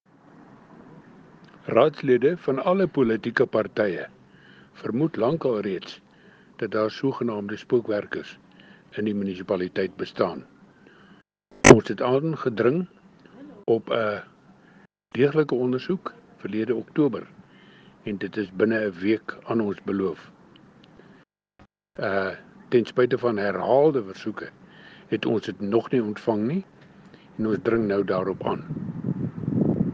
Afrikaans soundbites by Cllr Arnold Schoonwinkel and